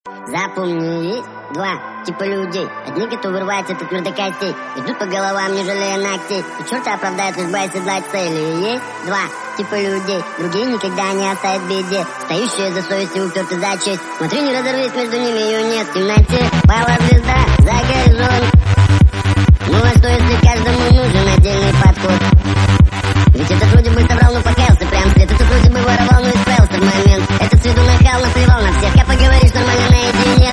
мужской голос
лирика